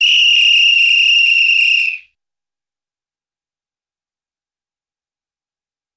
Звуки полицейского свистка